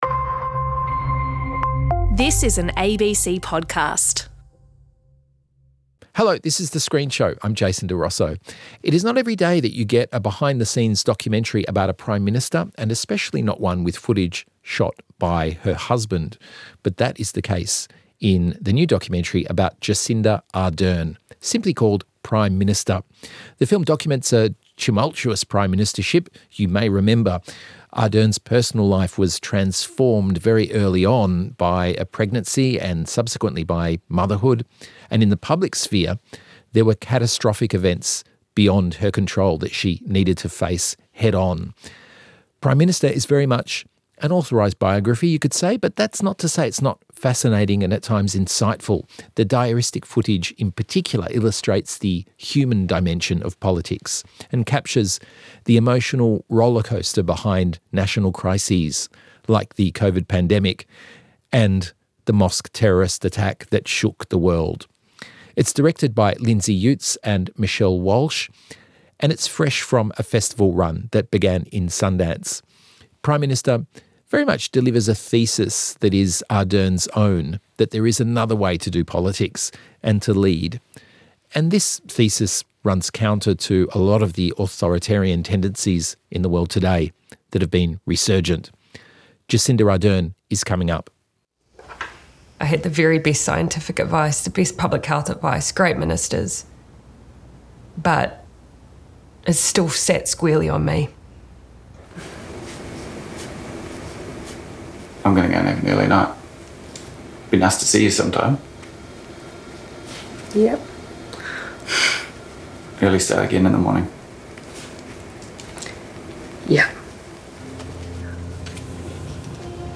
interviews the makers of the movies and tv you need to see.